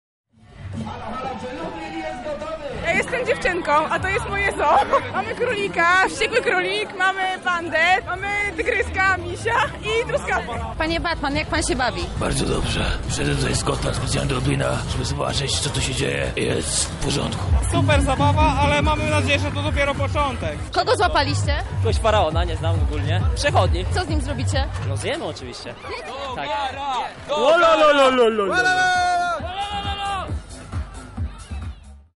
korowód studenci
korowód-studenci.mp3